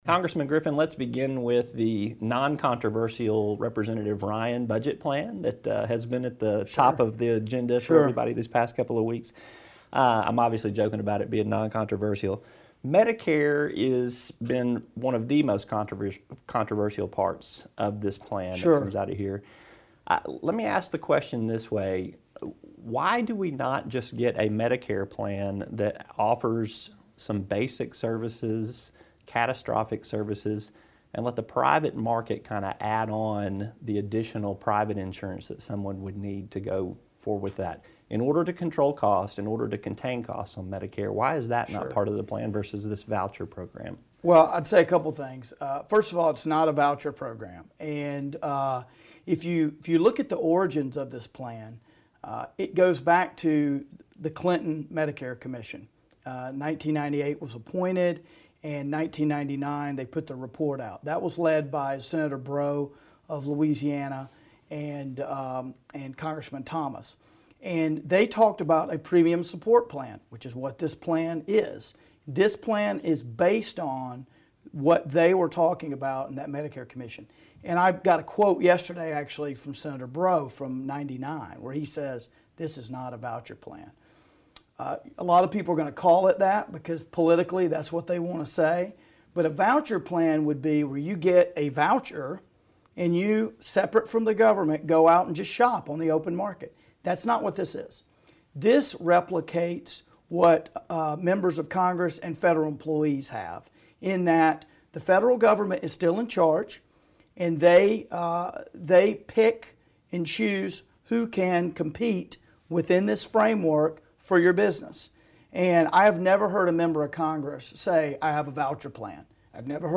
Arkansas' 2nd District Congressman Tim Griffin speaks